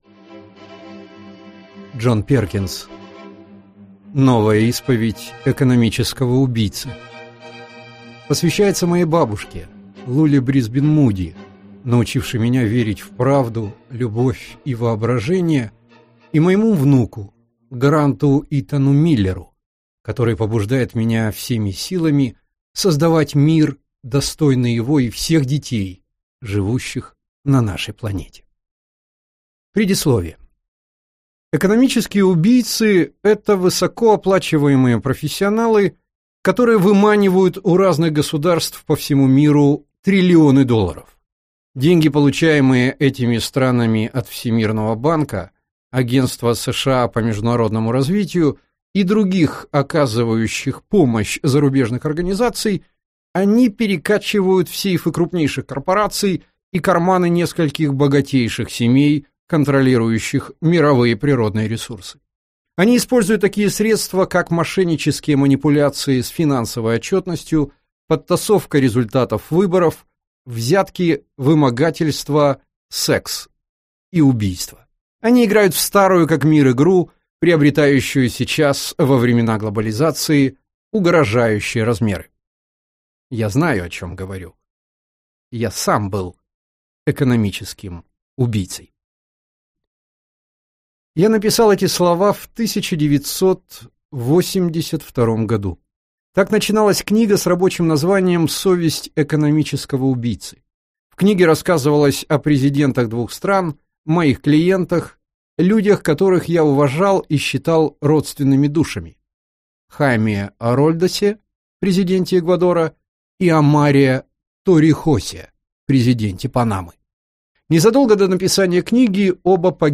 Аудиокнига Новая исповедь экономического убийцы - купить, скачать и слушать онлайн | КнигоПоиск